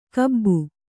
♪ kabbu